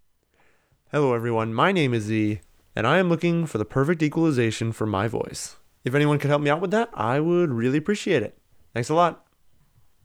I posted a speech sample in the attachments below. The audio sample has been normalized and compressed, so let me know if you need a raw sample.